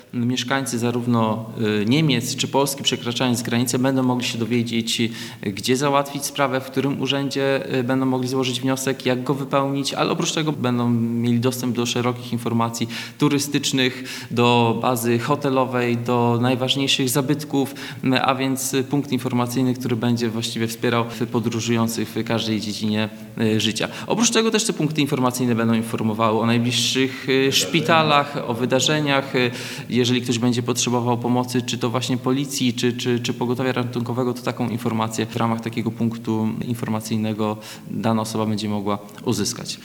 Rozmowy dotyczyły także współpracy w ratownictwie medycznym, czy przygotowaniu i wdrożeniu systemu punktów informacyjnych wzdłuż granicy polsko-niemieckiej. Mówi wicewojewoda Bartosz Brożyński.